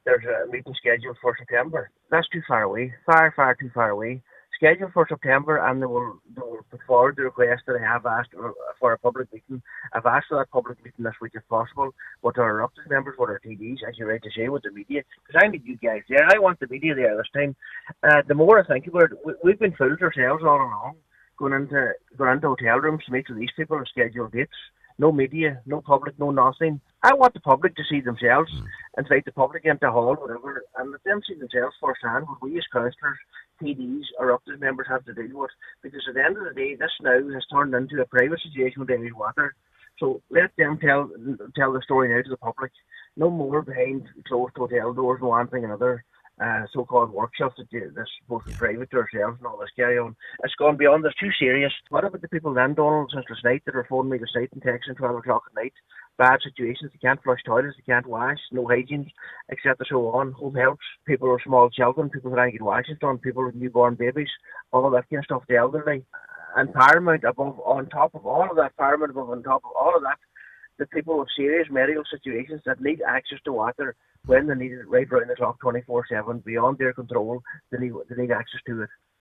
Cllr Michael McClafferty says the authority needs to take responsibility now: